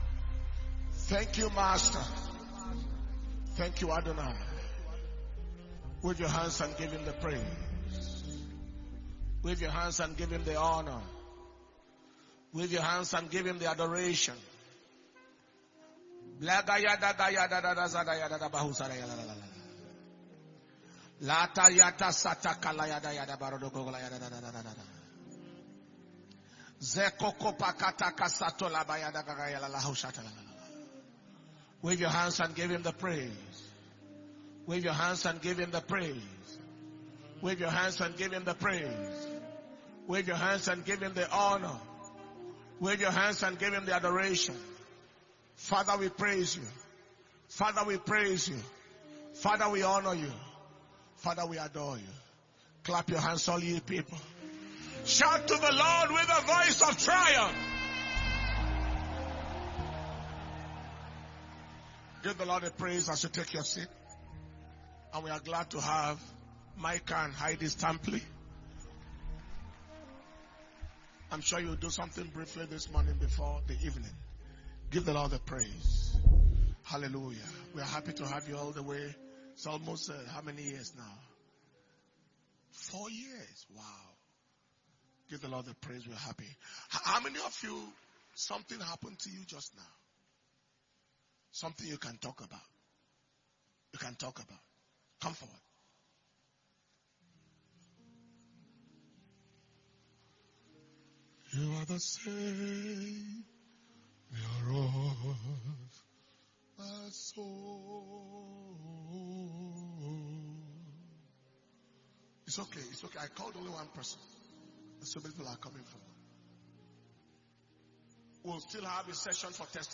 International Minister’s Fire Flaming Conference August 2022 Day 4 Morning Session